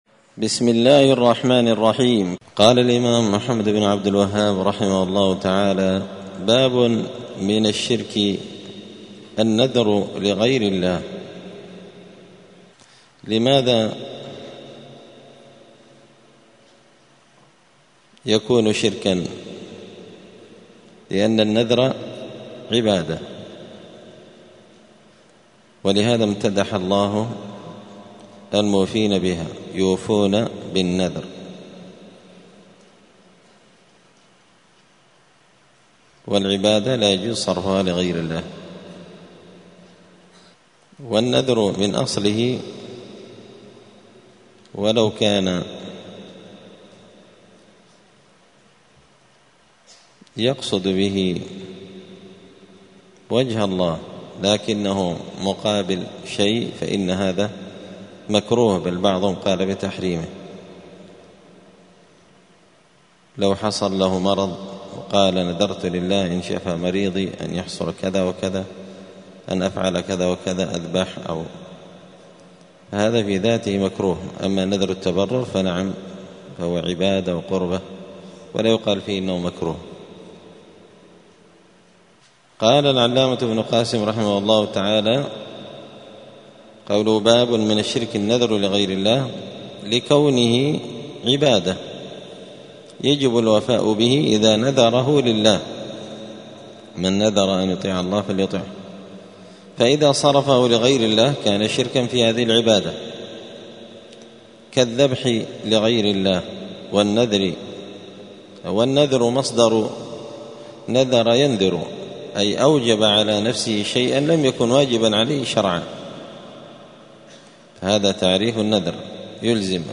دار الحديث السلفية بمسجد الفرقان بقشن المهرة اليمن
*الدرس الأربعون (40) {الباب الثاني عشر باب من الشرك النذر لغير الله…}*